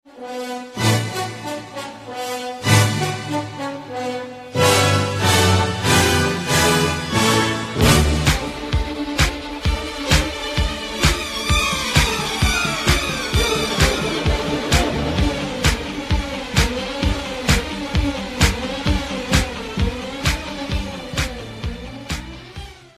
shtorm.mp3